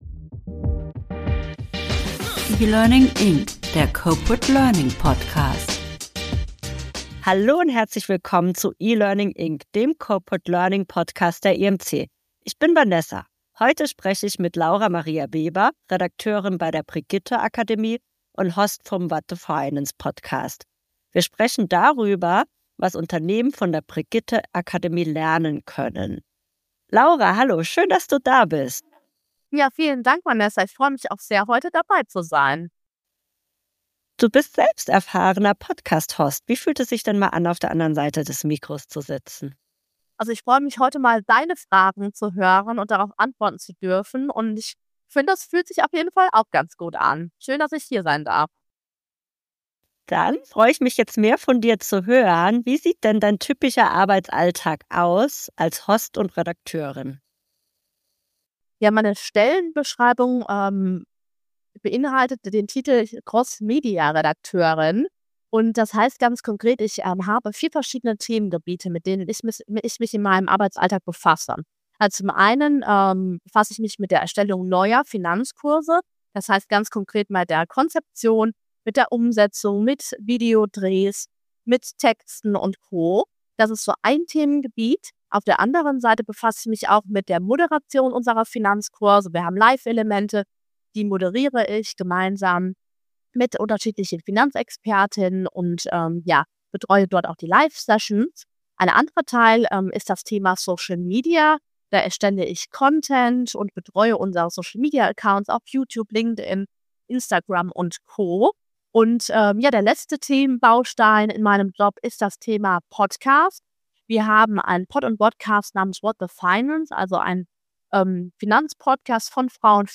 Takeaways aus dem Interview: Erfolgreiche Kurse setzen auf niedrige Einstiegshürden und klare Handlungsorientierung.